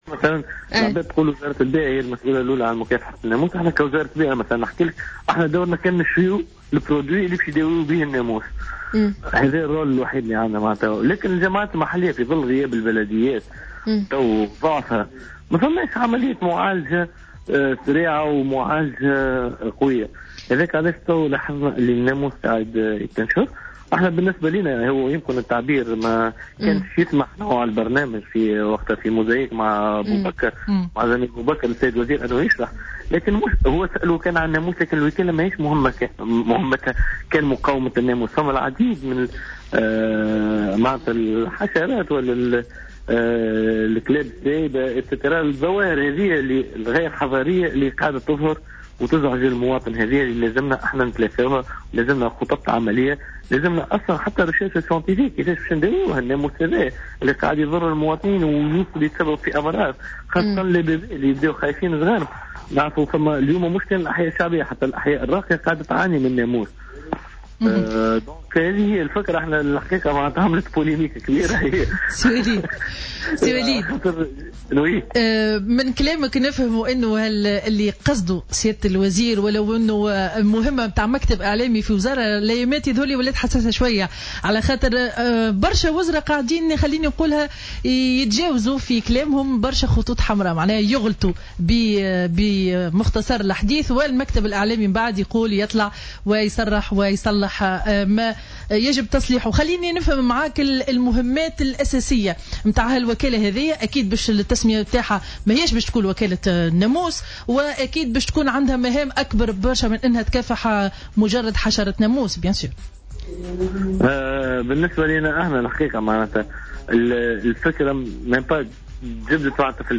وفي اتصال هاتفي مع "جوهرة أف أم" في برنامج "صباح الورد"